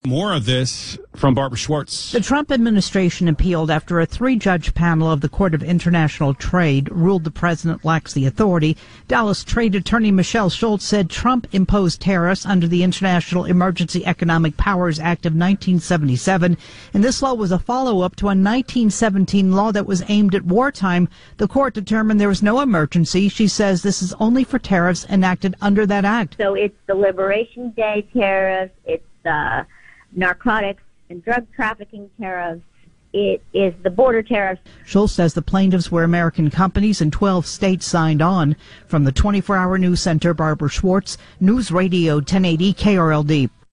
The new report discusses a legal challenge to the Trump administration's tariffs. A three-judge panel at the Court of International Trade ruled that the president lacked the authority to impose these tariffs under the International Emergency Economic Powers Act of 1977.